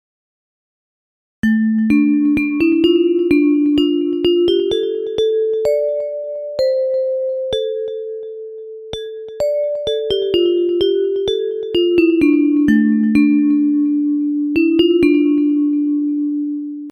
Bells Version
Music by: Israeli melody